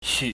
Cantonese Sounds-Mandarin Sounds
syut xue || shuo
syutMT.mp3